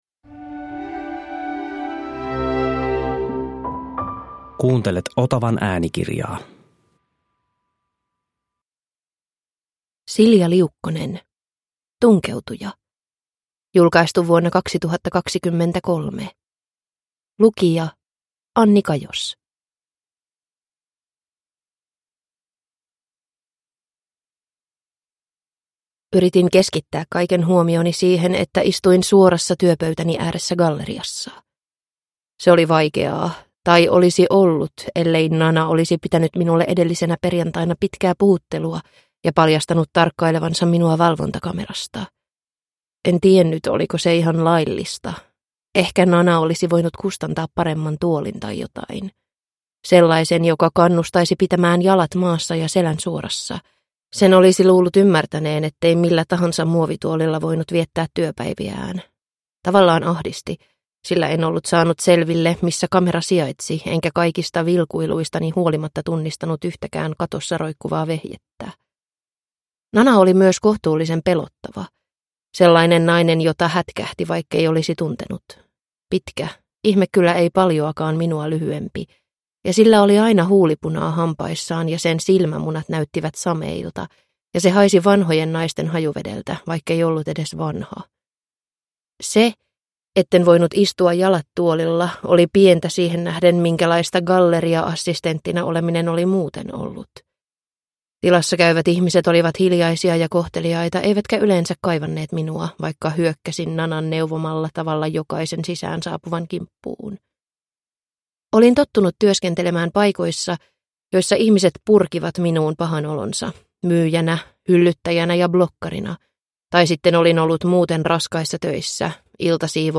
Tunkeutuja – Ljudbok – Laddas ner